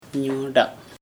[ɲoɖaʔ ] noun honey
Dialect: Hill Remo